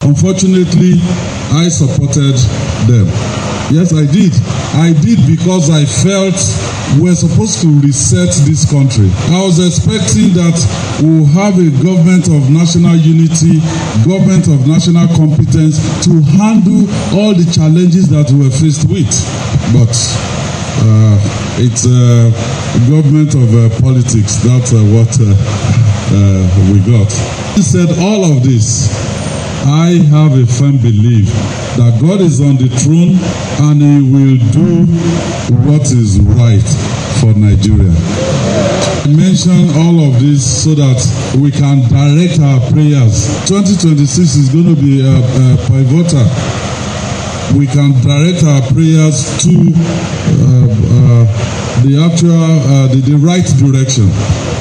Governor Makinde expressed this during a gathering in his Ikolaba residence in Ibadan, the state capital.